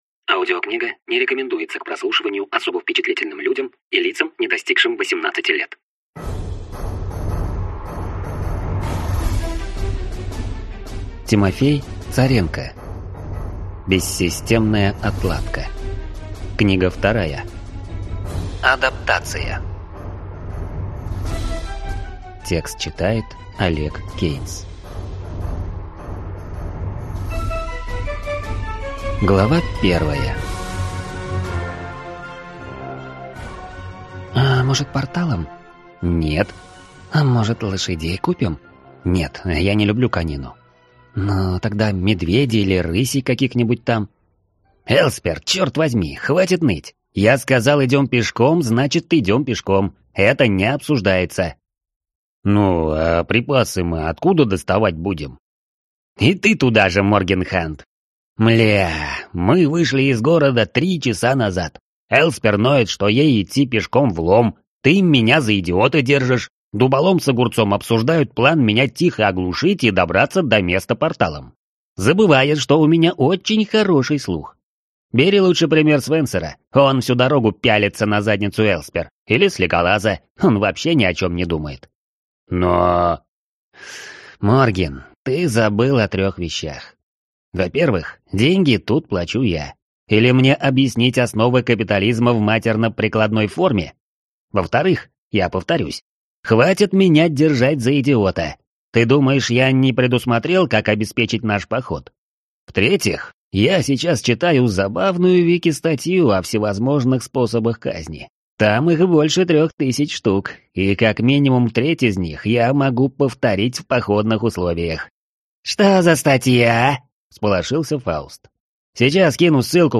Аудиокнига Бессистемная отладка. Адаптация | Библиотека аудиокниг